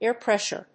アクセントáir prèssure